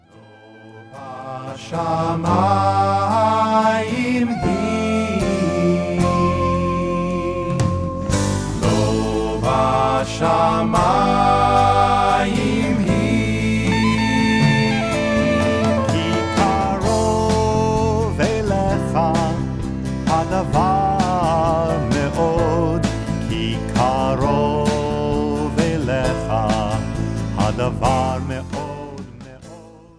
giving their music a uniquely modern sound.